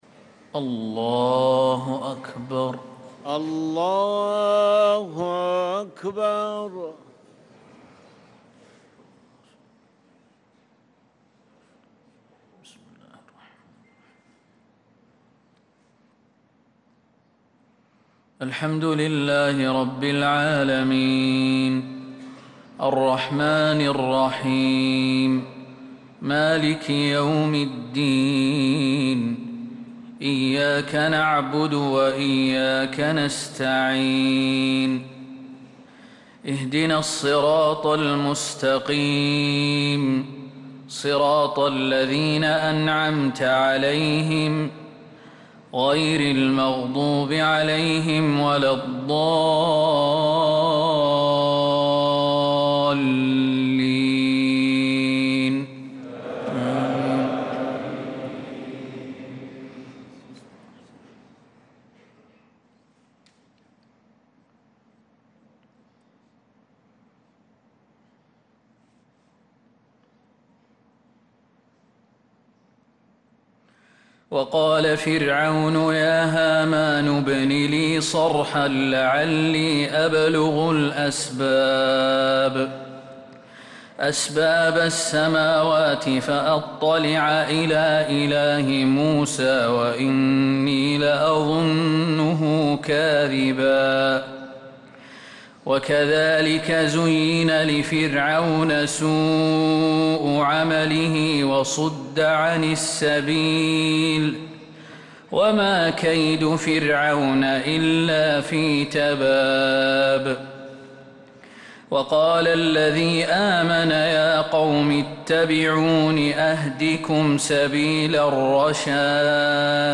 صلاة الفجر للقارئ خالد المهنا 21 ربيع الأول 1446 هـ
تِلَاوَات الْحَرَمَيْن .